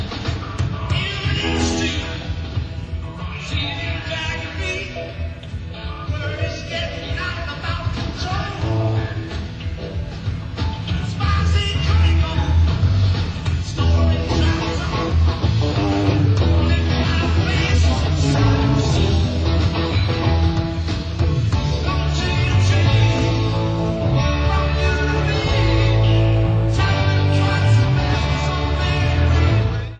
Format/Rating/Source: CD - C- - Audience
Comments: OK audience recording.